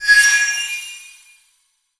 magic_shine_light_spell_05.wav